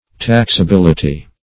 taxability - definition of taxability - synonyms, pronunciation, spelling from Free Dictionary
Search Result for " taxability" : Wordnet 3.0 NOUN (1) 1. liability to taxation ; The Collaborative International Dictionary of English v.0.48: Taxability \Tax`a*bil"i*ty\, n. The quality or state of being taxable; taxableness.